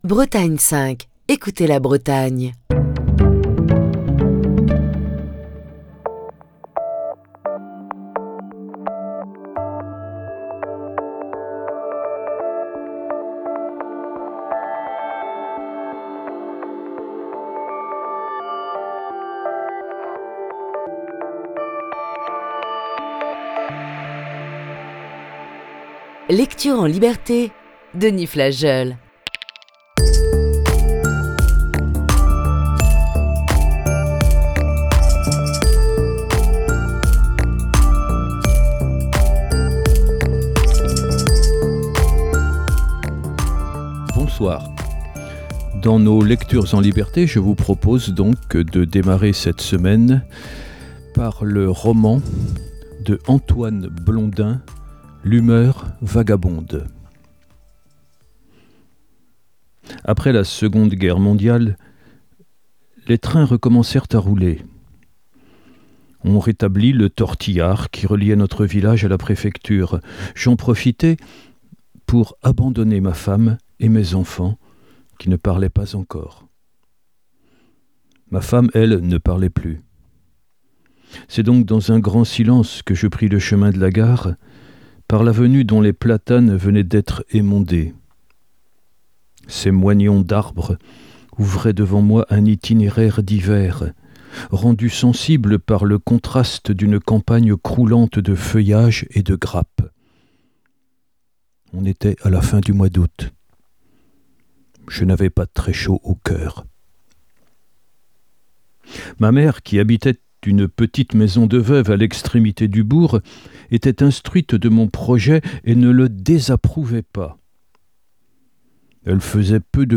lecture du roman